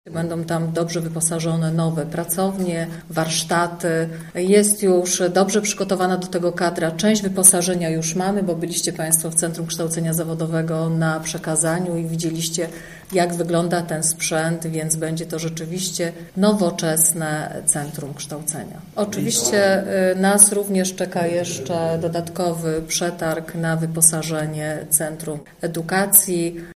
Inwestycja rozpocznie się na początku przyszłego roku i ma być gotowa w sierpniu 2021, tak by od września można było przenieść tam już uczniów Zespołu Szkół Mechanicznych, Zespołu Szkół Budowlanych i Samochodowych oraz Centrum kształcenia Zawodowego. Mówi wiceprezydent Małgorzata Domagała: